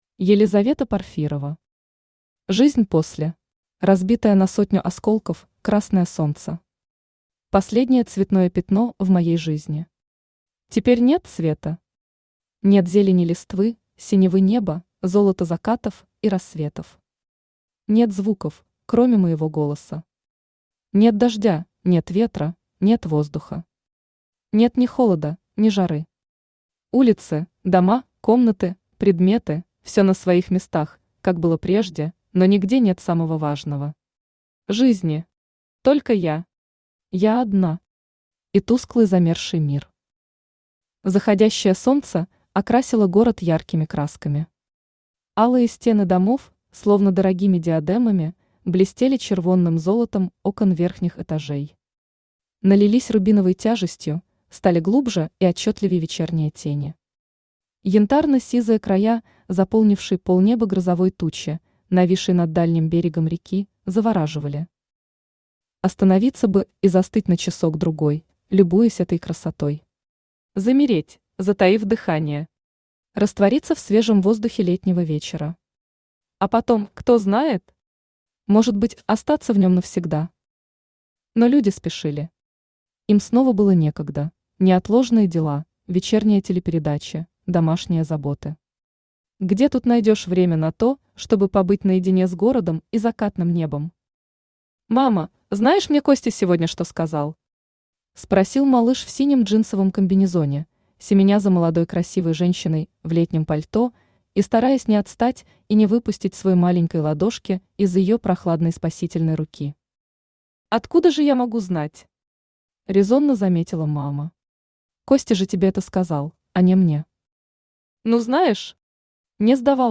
Аудиокнига Жизнь после | Библиотека аудиокниг
Aудиокнига Жизнь после Автор Елизавета Порфирова Читает аудиокнигу Авточтец ЛитРес.